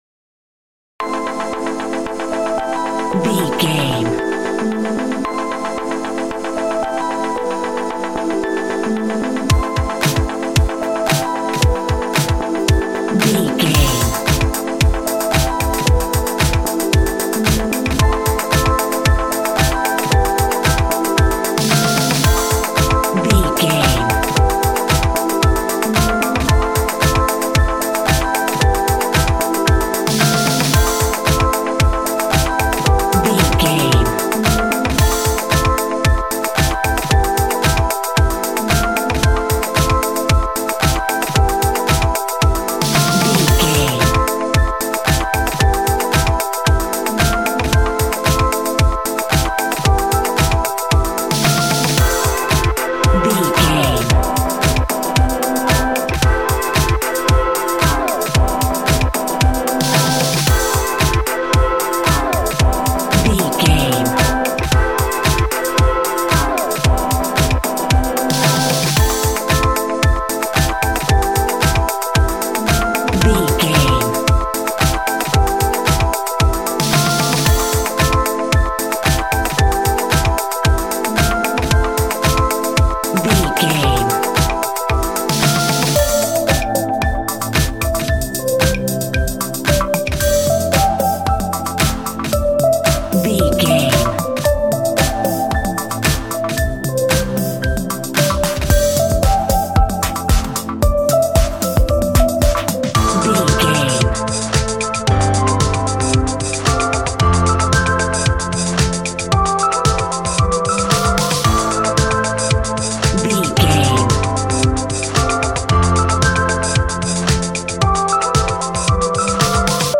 Aeolian/Minor
groovy
mellow
relaxed
synthesiser
drum machine